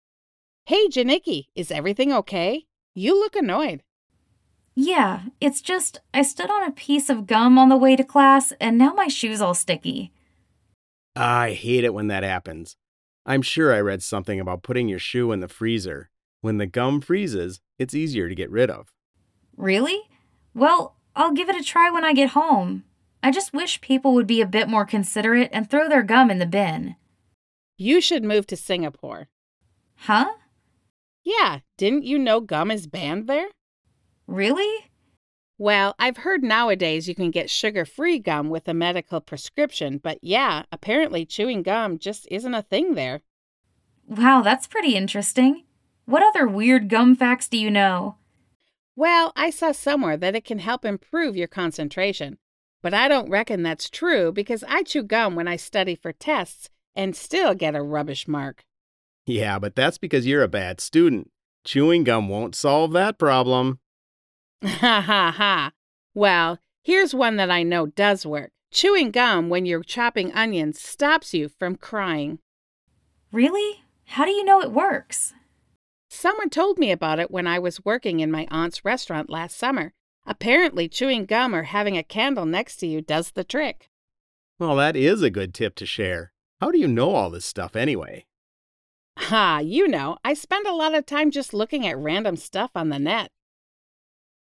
RH4 4.2 Conversation.mp3